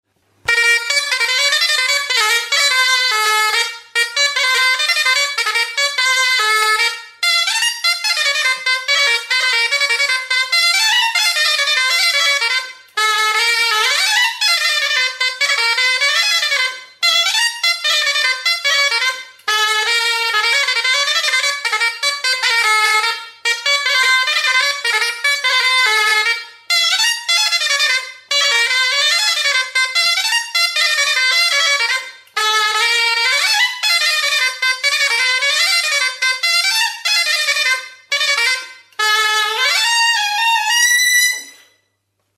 Instrumentos de músicaBOMBARDE
Aerófonos -> Lengüetas -> Doble (oboe)
Grabado con este instrumento.
Oboe motako mihi bikoitzeko soinu-tresna da.